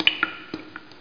drip2.mp3